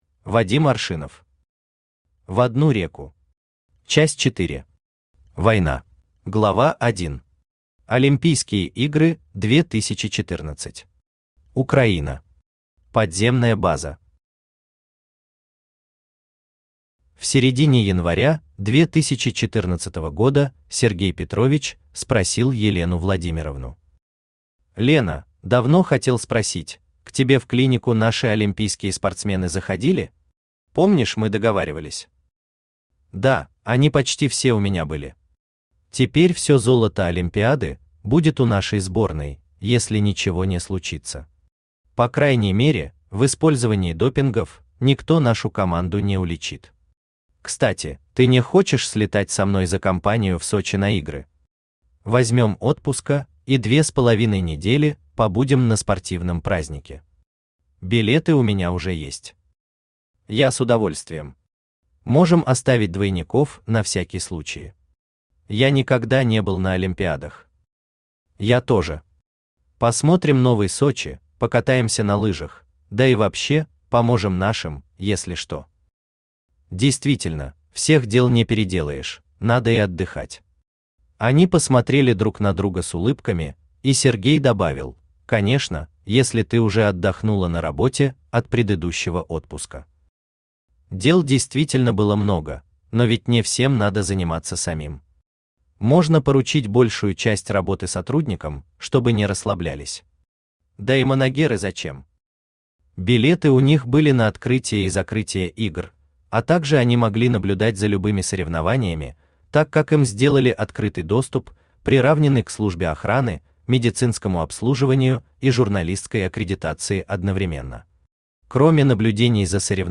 Читает: Авточтец ЛитРес
Аудиокнига «В одну реку. Часть 4. Война».